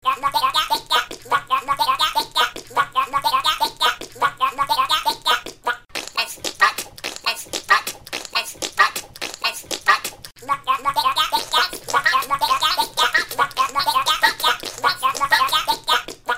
Kategori Sjove